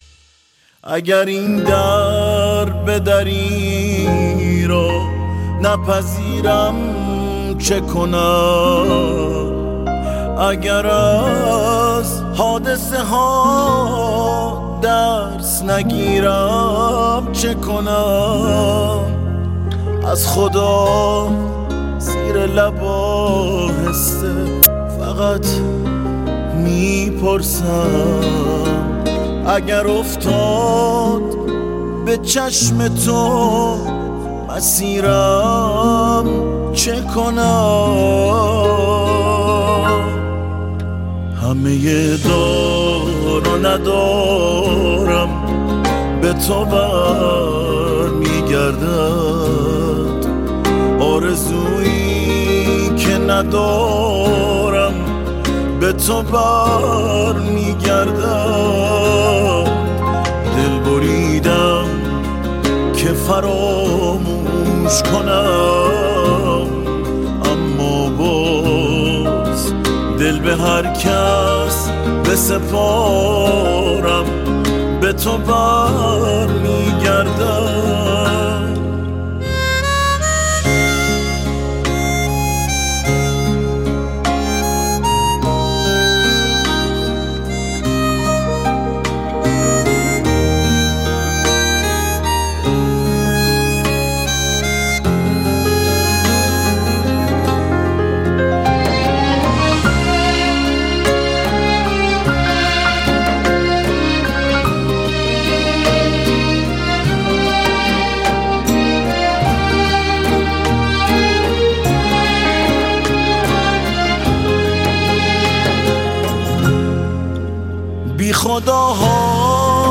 آهنگ تیتراژ با صدای